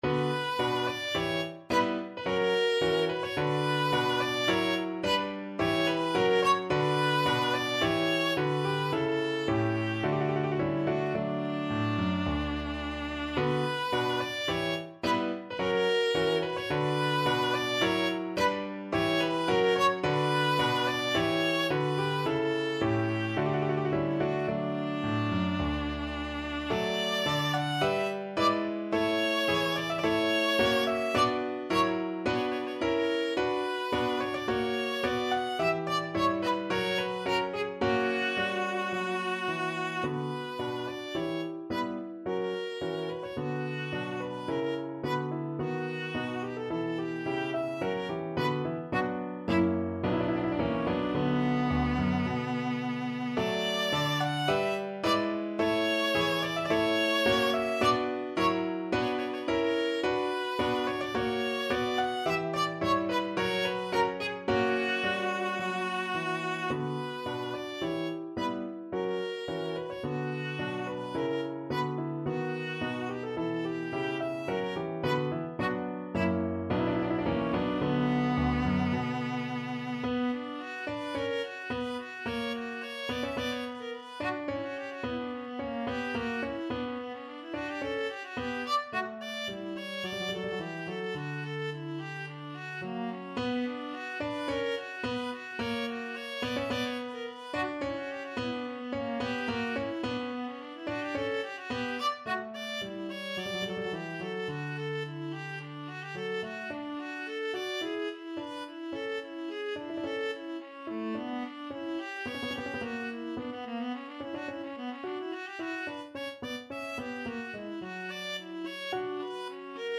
Viola version
3/4 (View more 3/4 Music)
~ = 54 Moderato
A4-F#6
Classical (View more Classical Viola Music)